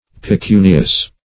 pecunious - definition of pecunious - synonyms, pronunciation, spelling from Free Dictionary
Search Result for " pecunious" : The Collaborative International Dictionary of English v.0.48: Pecunious \Pe*cu"ni*ous\, a. [L. pecuniosus, fr. pecunia: cf. F. p['e]cunieux.]